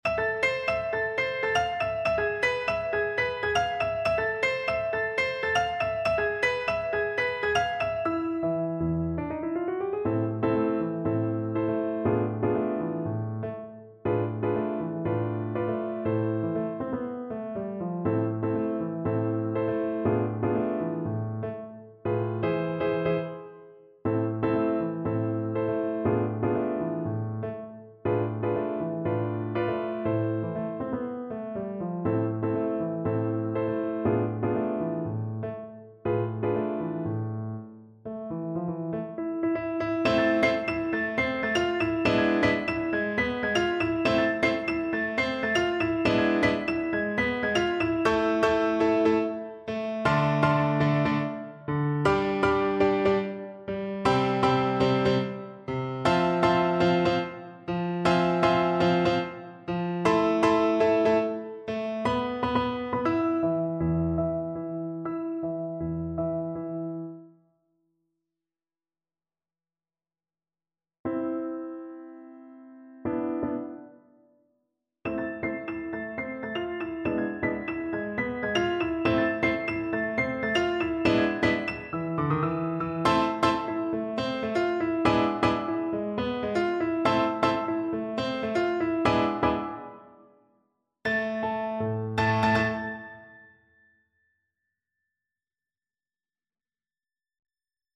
A firey salsa-inspired piece.
Energico =120
Jazz (View more Jazz Violin Music)